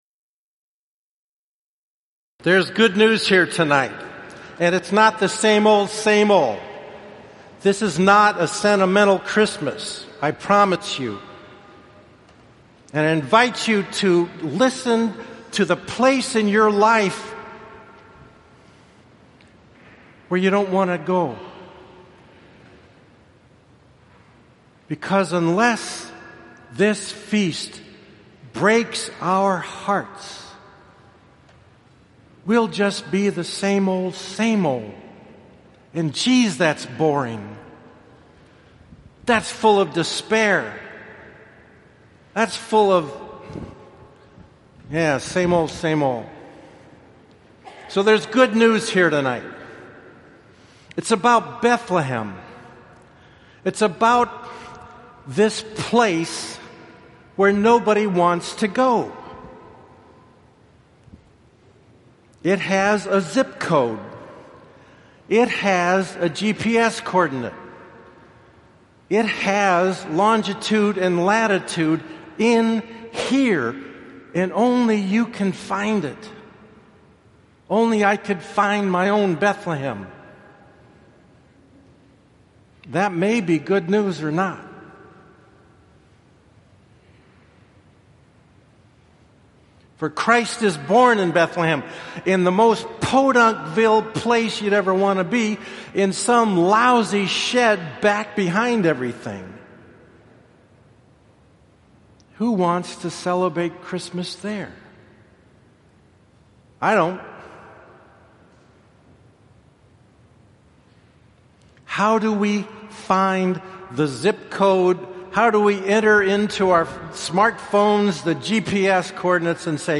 christmas-midnight-mass-2015.mp3